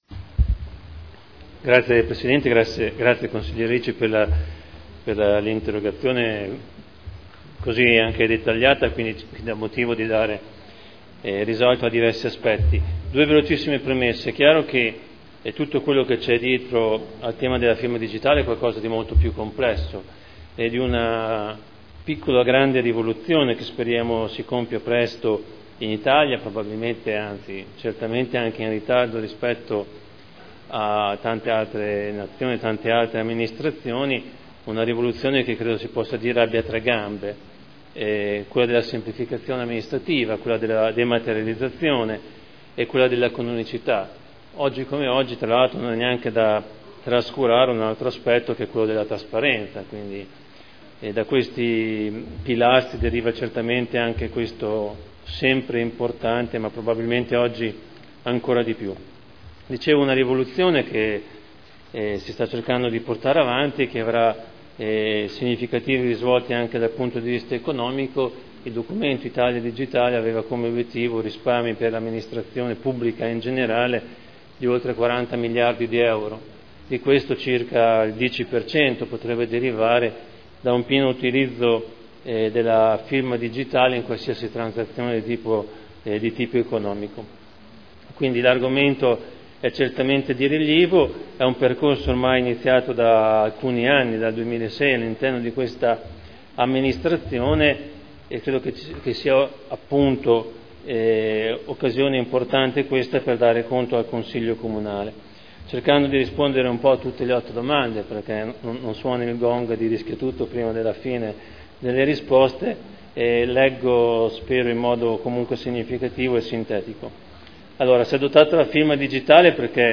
Seduta del 24/09/2012 Risponde a Interrogazione del consigliere Ricci (Sinistra per Modena) avente per oggetto: “Firma digitale” (presentata il 2 luglio 2012 – in trattazione il 24.9.2012)